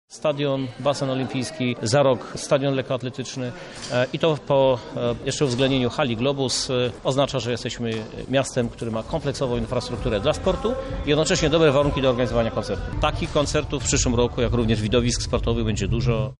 Chciałbym, żeby lublinianie byli dumni z obiektów sportowych na europejskim poziomie – podkreśla prezydent Lublina, Krzysztof Żuk.